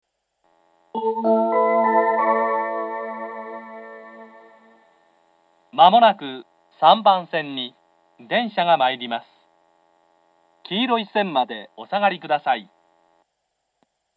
接近放送があり、「東海道型」の放送が使用されています。
３番線接近放送 男声の放送です。